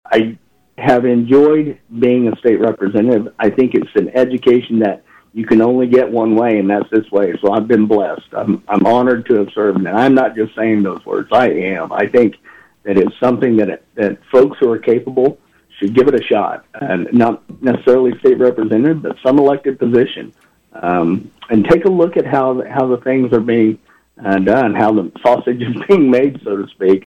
Also retiring is House 76th District Representative Eric Smith, who has served the district the past eight years. Earlier on KVOE’s Morning Show, Smith says he enjoyed serving the district.